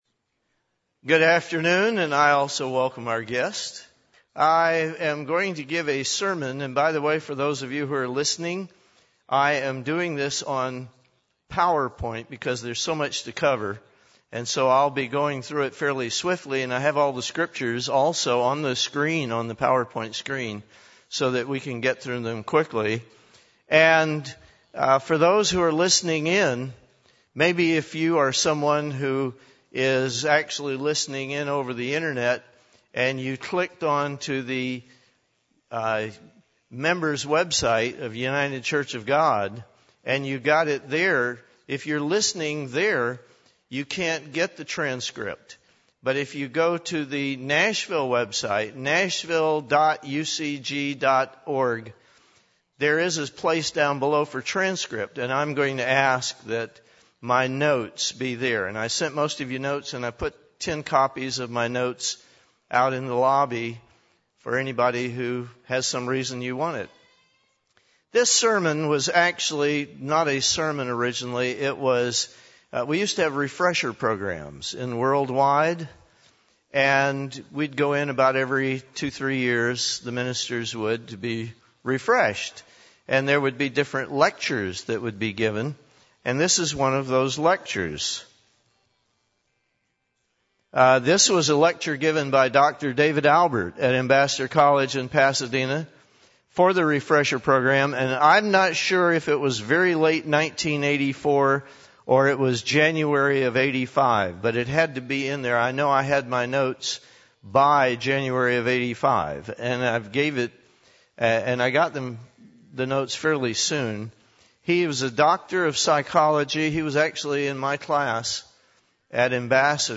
UCG Sermon Notes SANITY Note